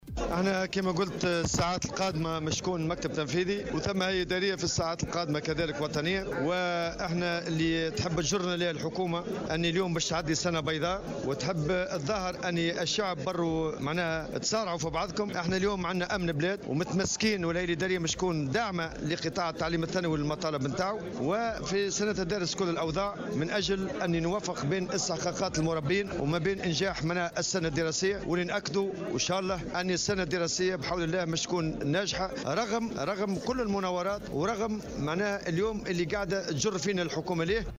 وأضاف في تصريح لمراسل "الجوهرة أف أم" على هامش المؤتمر العادي للإتحاد الجهوي للشغل بتوزر أن الهيئة ستتدارس كل الأوضاع من أجل التوفيق بين إستحقاقات المربين وبين إنجاح السنة الدراسية "رغم كل المناورات"، وفق تعبيره.